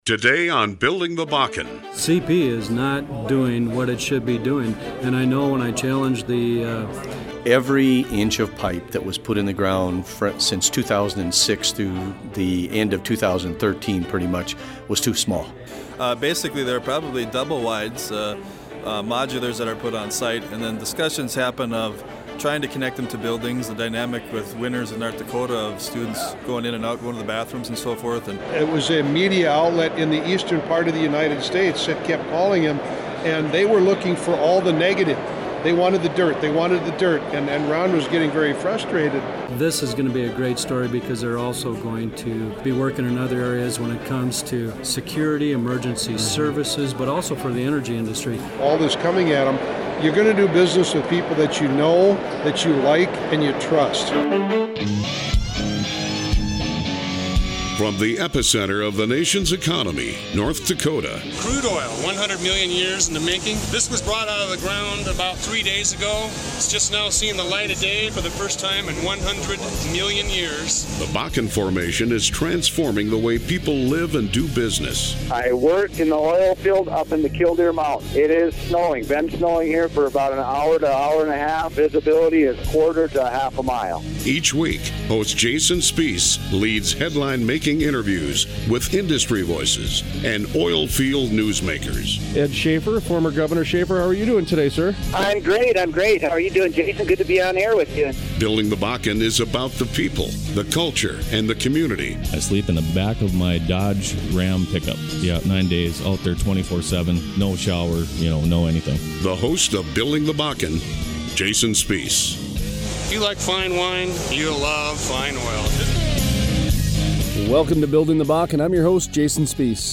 Interview: North Dakota Agriculture Commissioner Doug Goehring Building the Bakken Episode 62 Segment 1: Current Events Doug Goehring discusses the